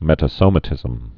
(mĕtə-sōmə-tĭzəm) also met·a·so·ma·to·sis (-sōmə-tōsĭs)